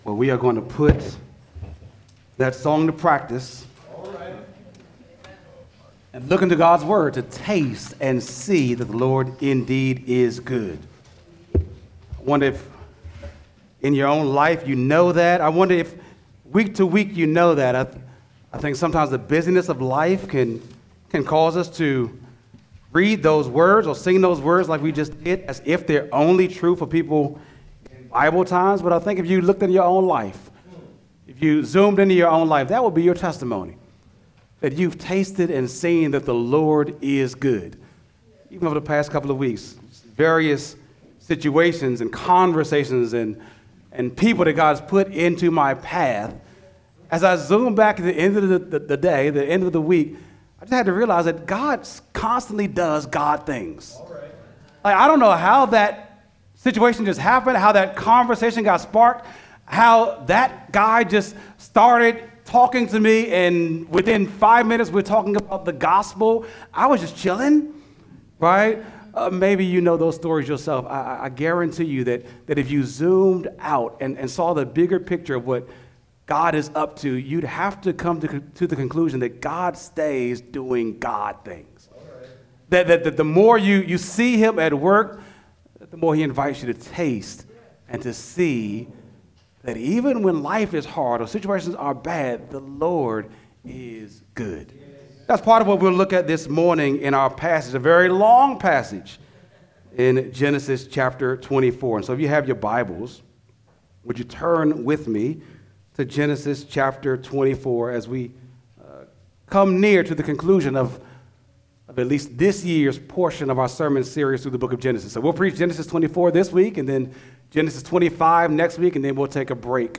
Temple Hills Baptist Church Sermons
sunday-sermon-5-18-25.mp3